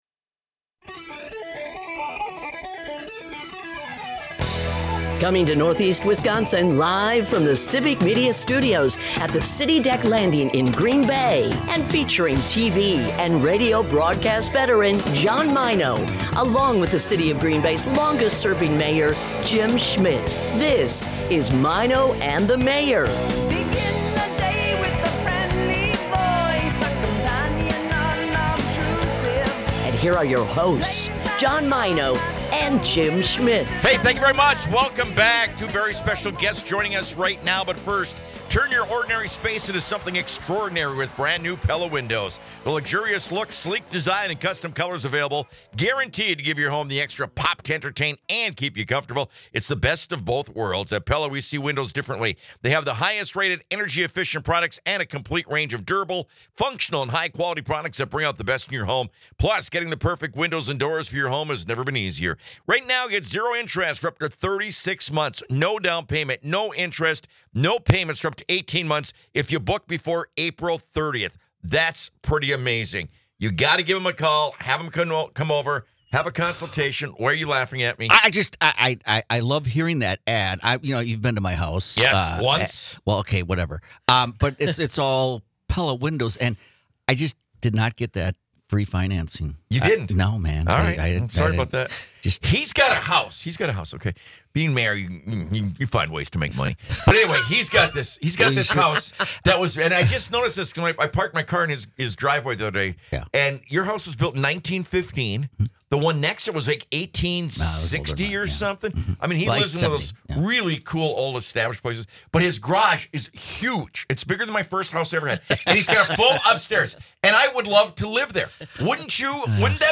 Another great hour from Heights Pub & Parlor!